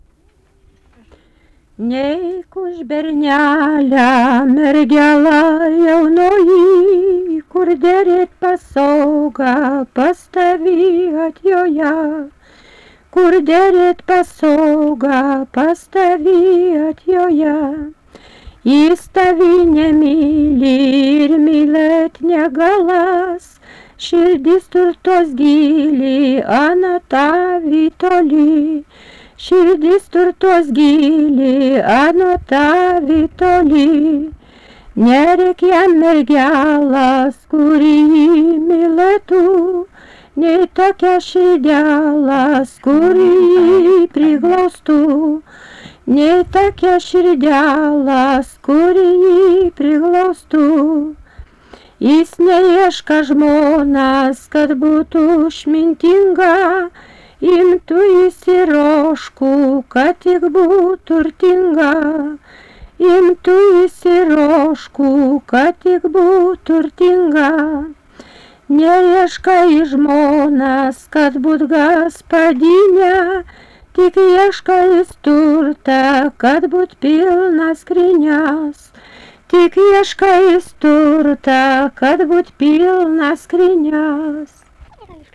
daina
Erdvinė aprėptis Ryžiškė Rotkalnis
Atlikimo pubūdis vokalinis